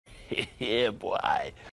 Category 😂 Memes